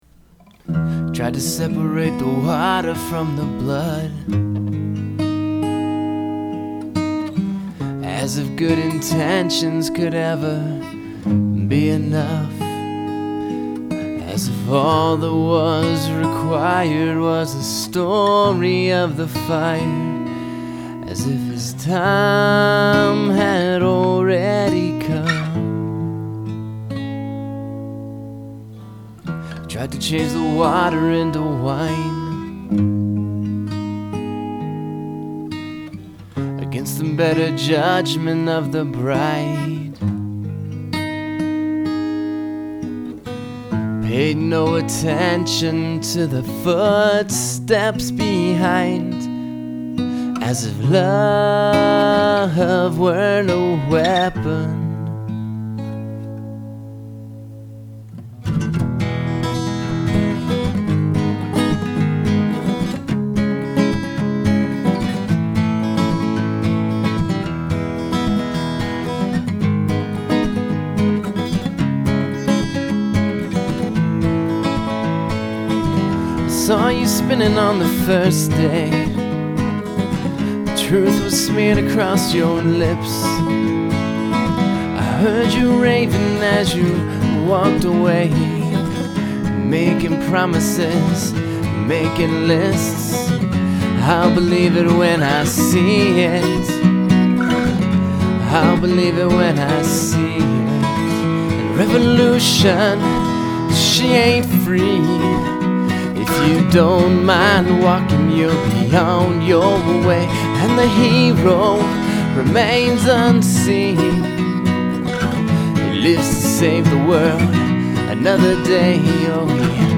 Must include three different tempos